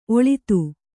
♪ oḷitu